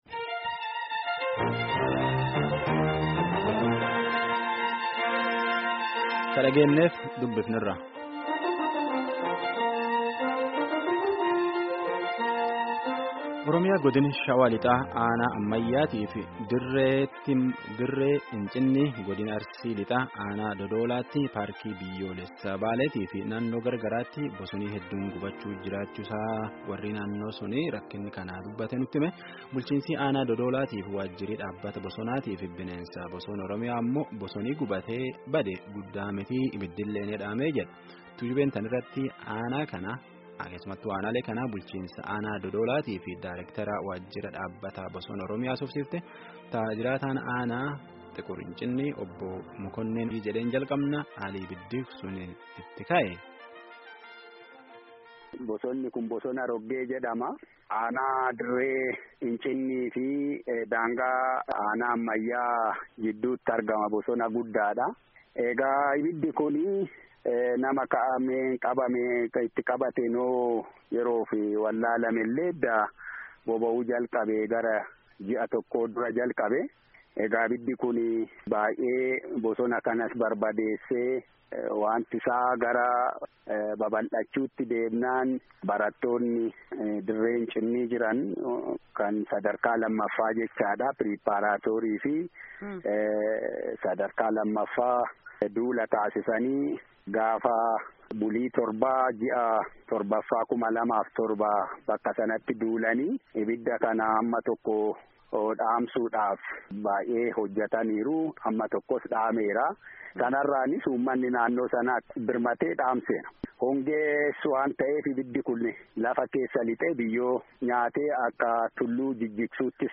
Gaaffii fi deebii geggeedffame kutaa 2ffaa armaan gaditti caqasaa